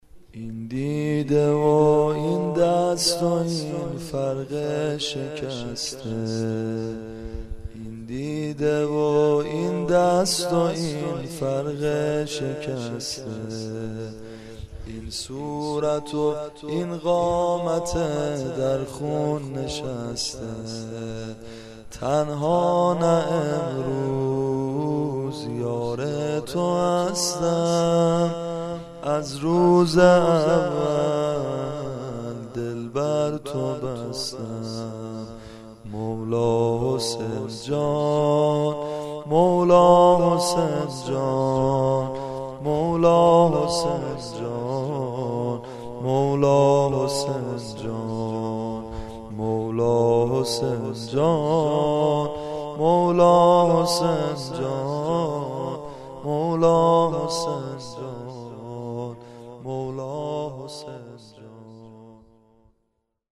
در استودیوی عقیق